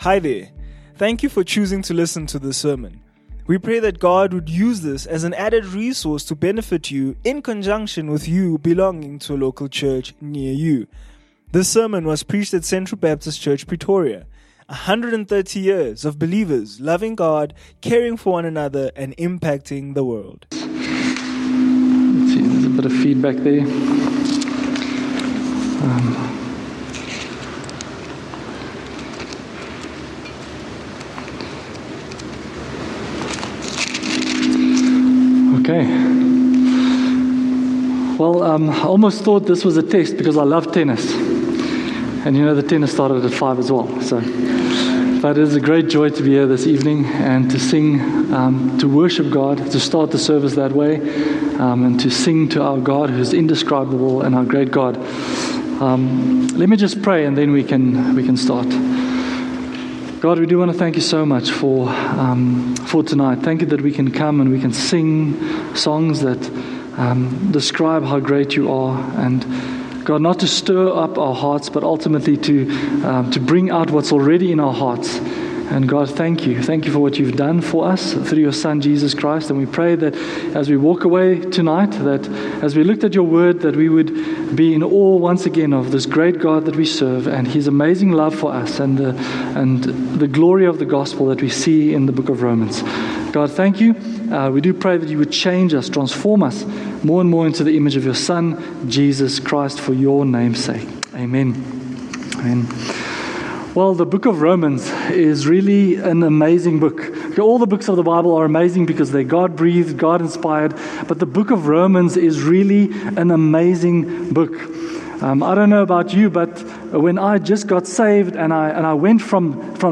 Expository preaching grounds the message in the text.